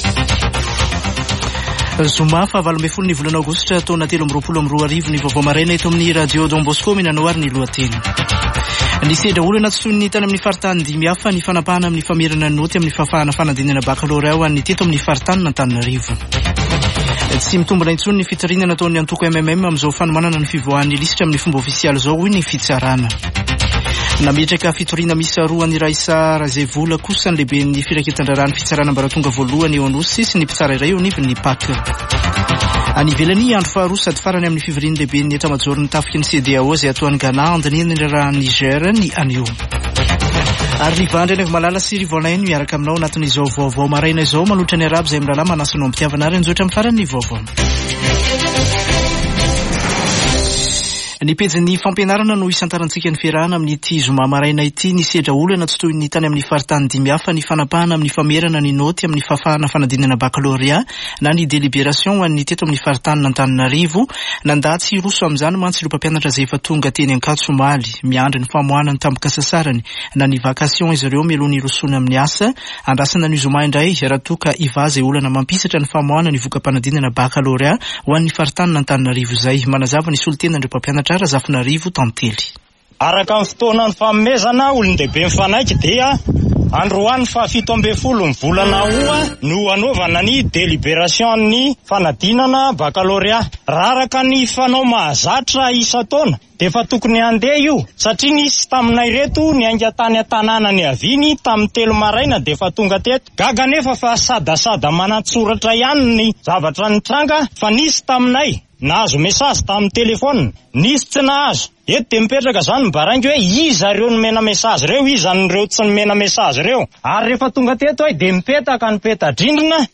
[Vaovao maraina] Zoma 18 aogositra 2023